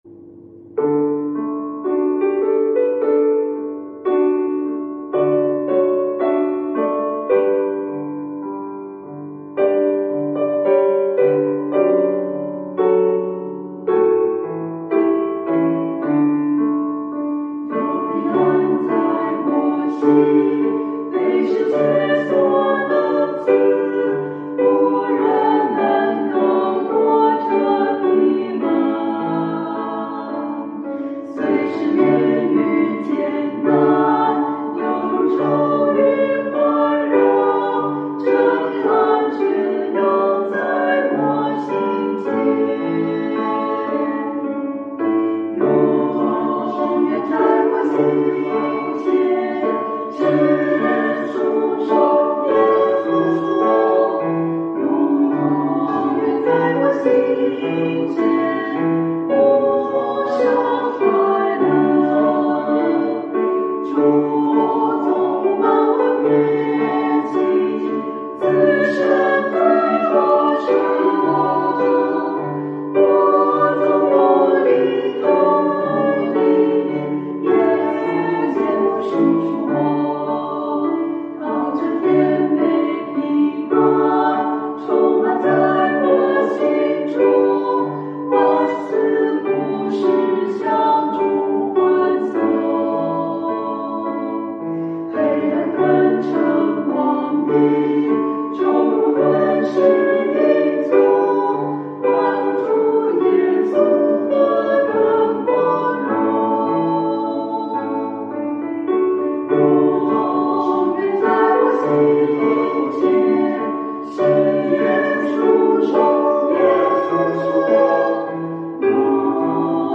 赞美诗 | 有平安在我心